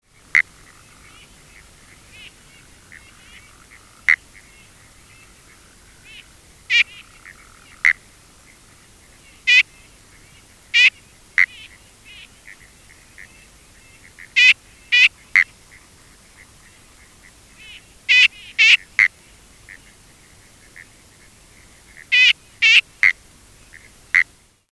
litoria_nudidigita.mp3